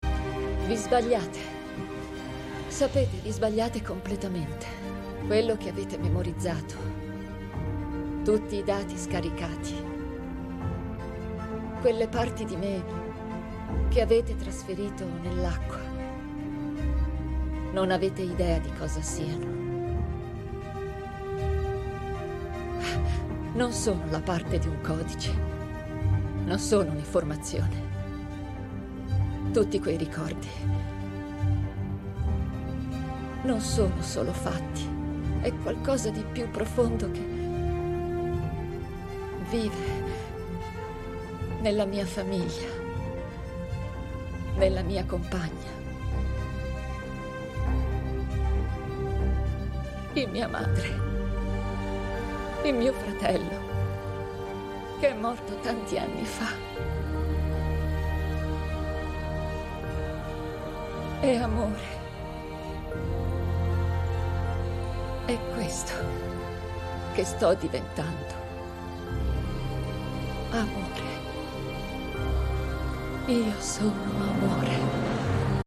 nel telefilm "Years and Years", in cui doppia Jessica Hynes.